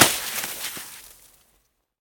snowhit.wav